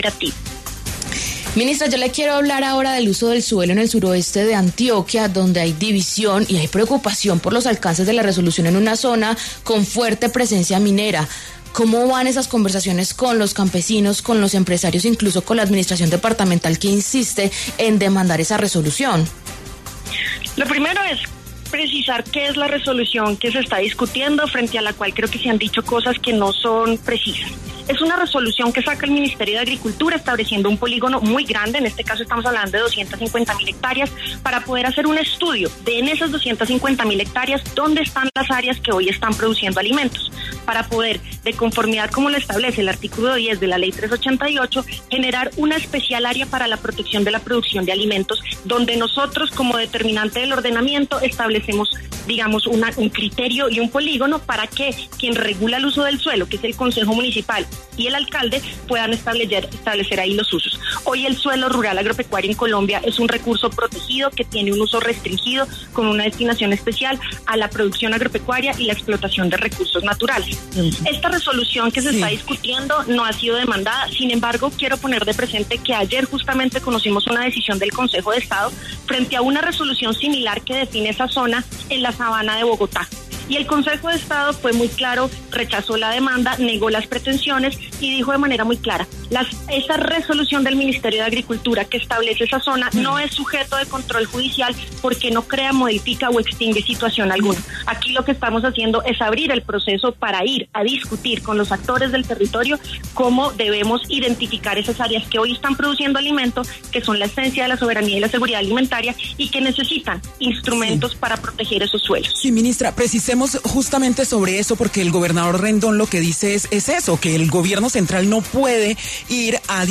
La ministra de Agricultura, Martha Carvajalino, pasó por los micrófonos de La W y habló acerca del uso del suelo en el suroeste de Antioquia donde hay división y preocupación por los alcances de la resolución en una zona con presencia minera.